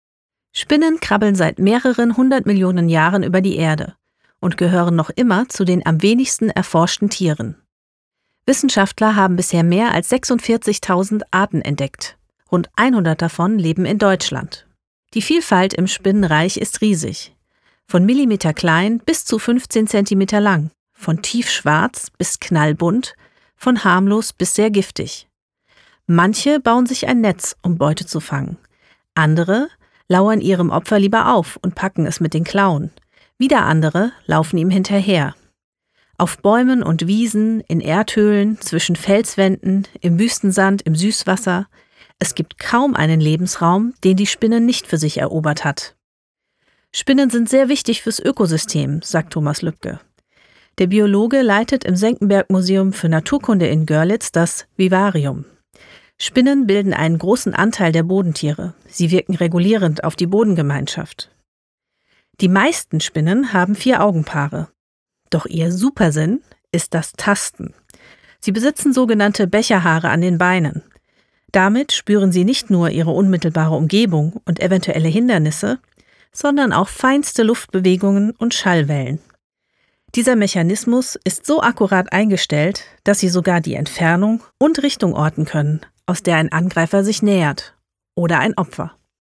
Professionelle Studiosprecherin.
Sachtext